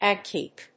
/éɪsìːdíːsìː(米国英語)/
アクセントÀC/[N16-A154]C 発音記号・読み方/éɪsìːdíːsìː/